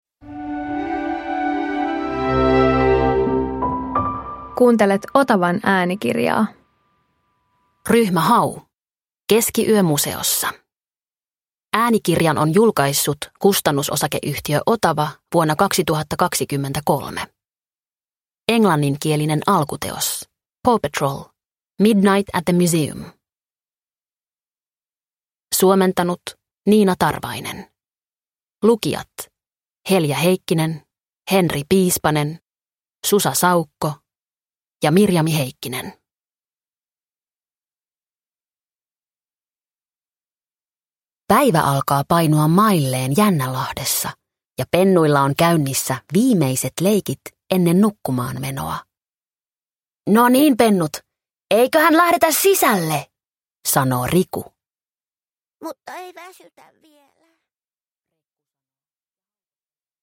Ryhmä Hau - Keskiyö museossa – Ljudbok – Laddas ner